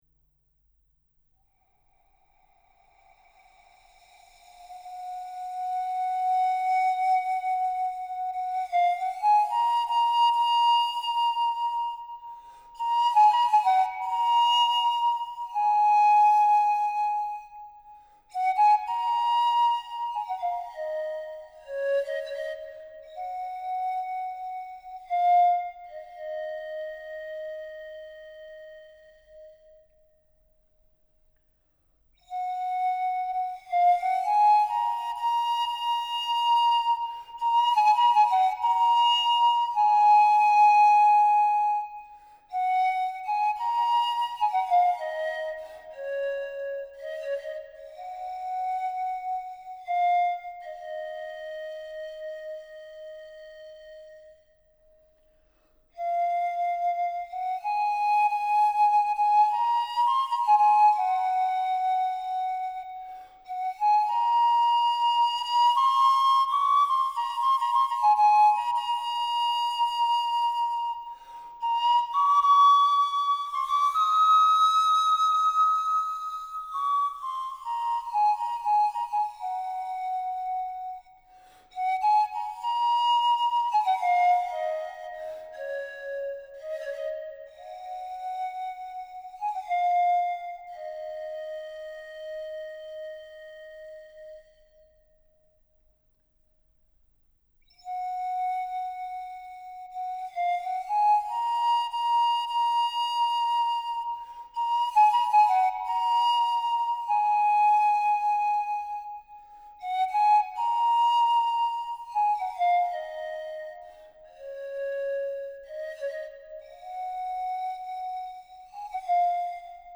La flûte de Pan médiévale européenne